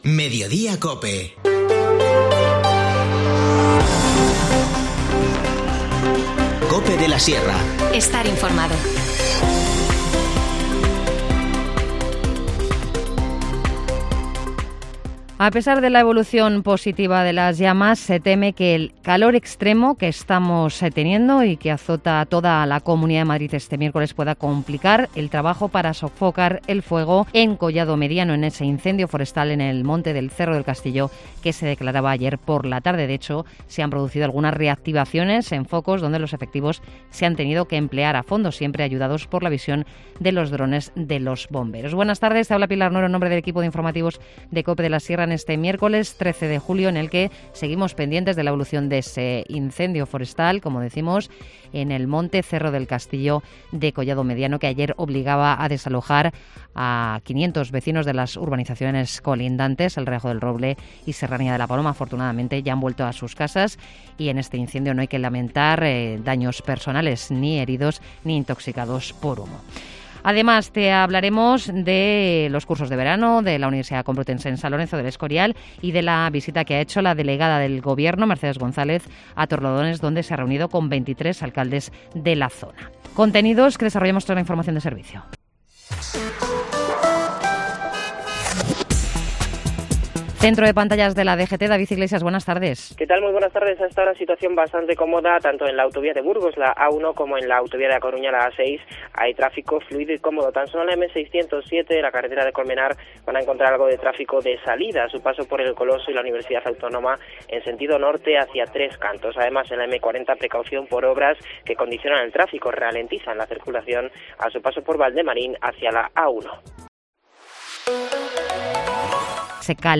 Informativo Mediodía 13 julio